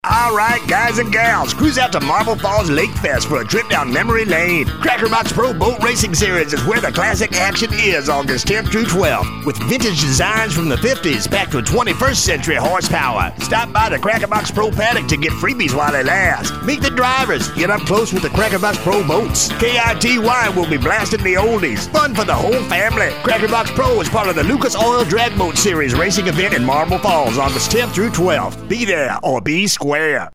Crackerbox Pro Lakefest 30 sec Radio Spot